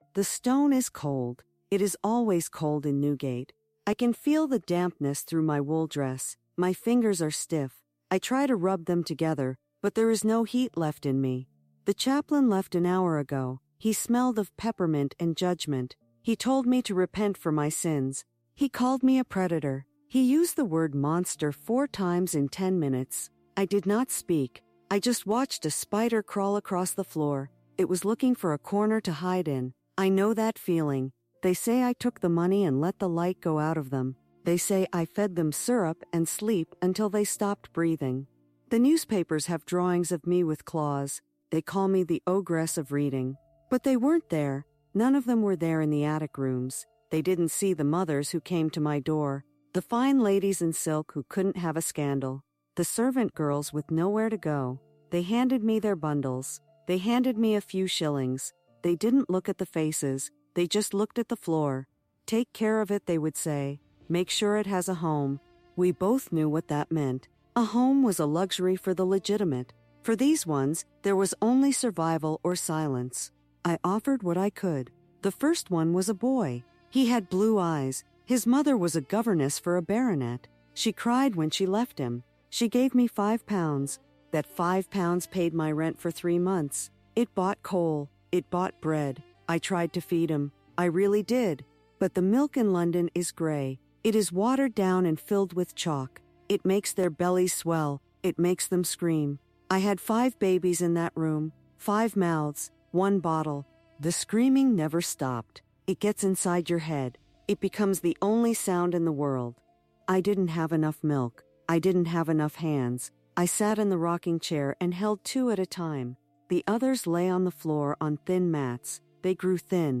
Subscribe for more deep dives into the high-stakes emotional trials of women throughout history, delivered in a raw, intimate TTS-optimized format designed for maximum immersion.